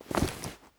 looting_10.ogg